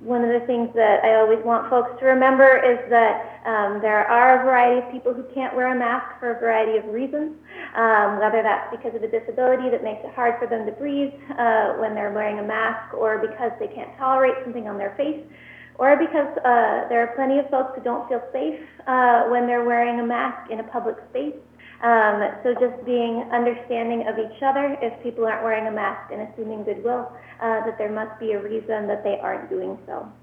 At Monday morning’s covid-19 briefing, County health Officer Dr. Allison Berry Unthank began by reporting that there are no new positive cases in Clallam County, no tests pending and the last known patient recovering at home. She then spoke about today’s mandate for employee face masks.